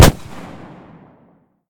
pistol-shot-02.ogg